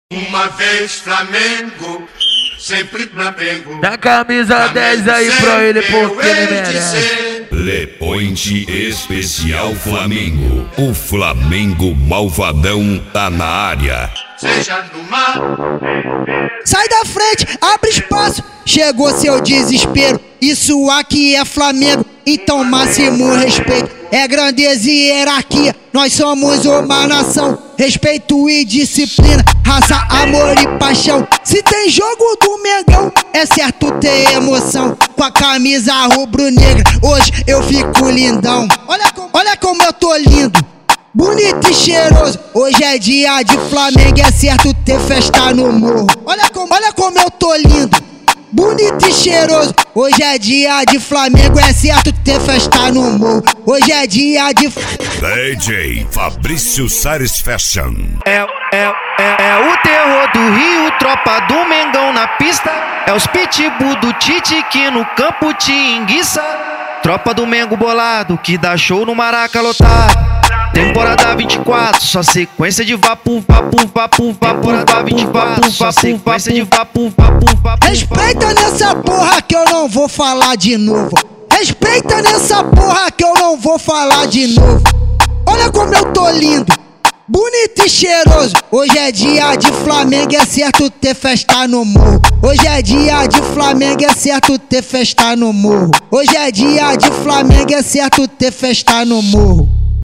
Funk
Mega Funk